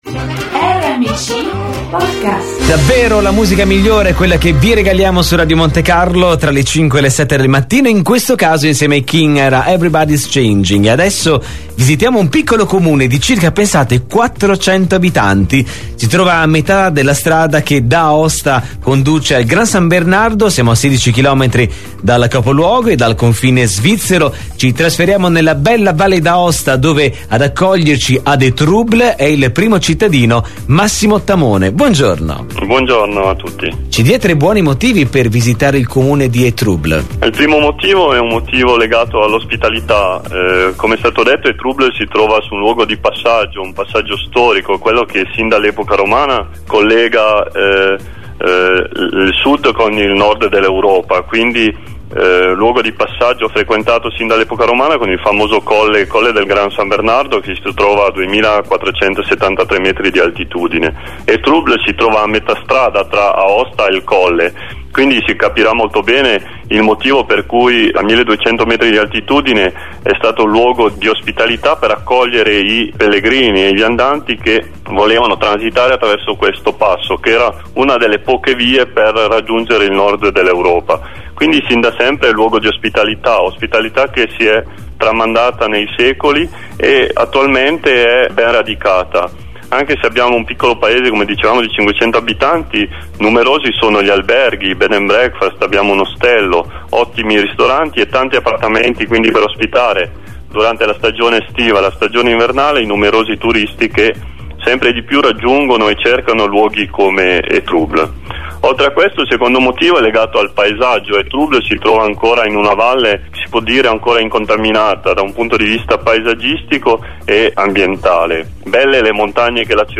ogni giorno un Sindaco viene intervistato per spiegare con 3 motivi, perché bisogna visitare il proprio Comune.